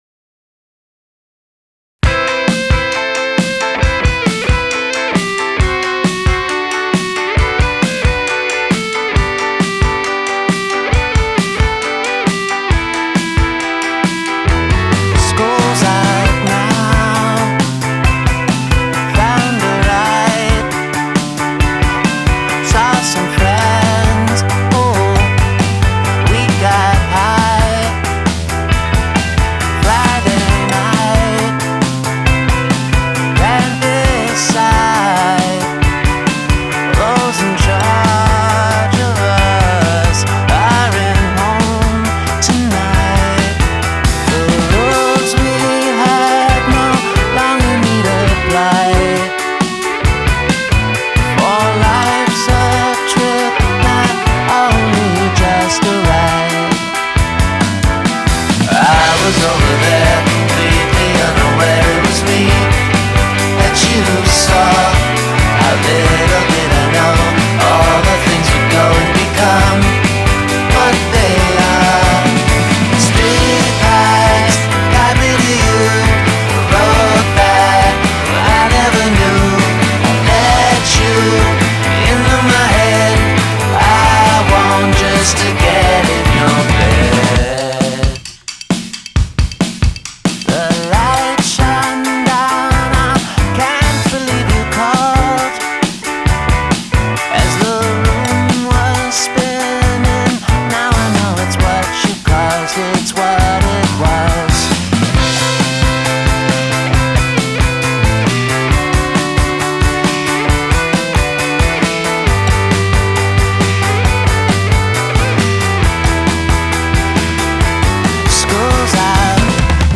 Rock Funk